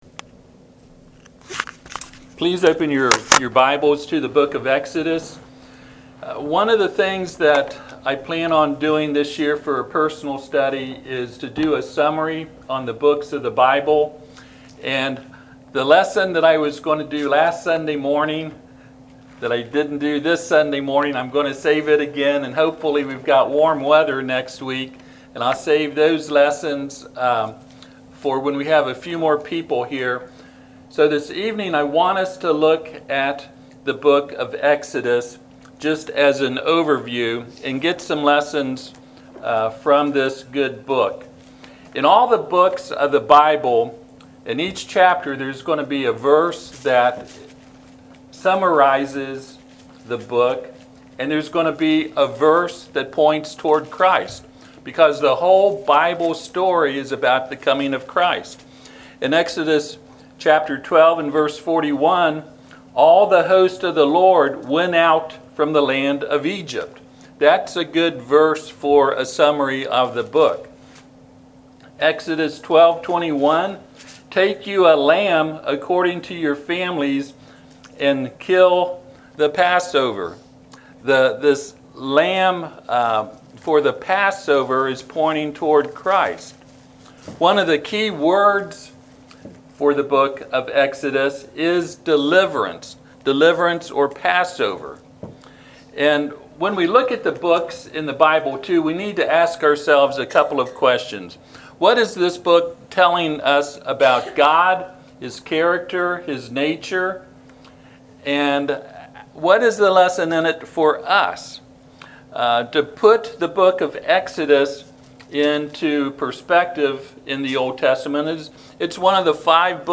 Passage: Exodus 12:21 Service Type: Sunday PM